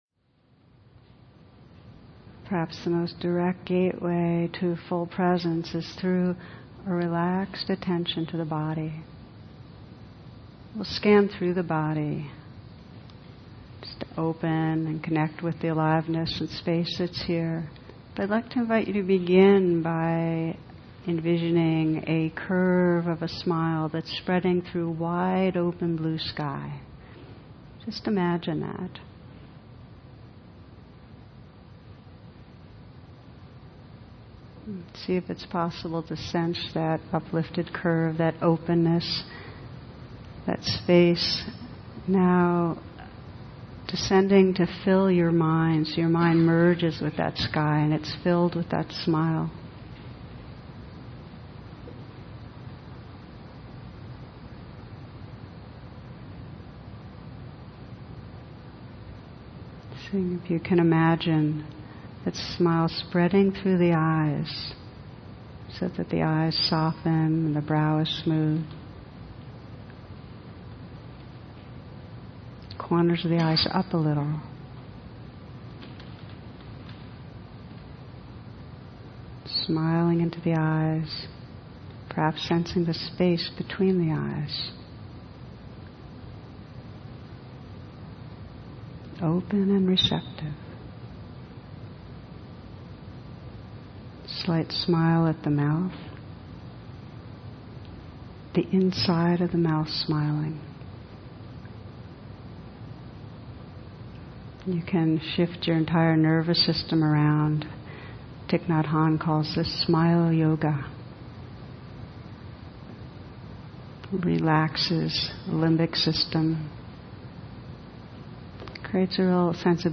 Many people new to meditation find it easier to start with a guided meditation.
A guided meditation is a meditation in which somebody (an experienced meditator) guides the beginner with his voice.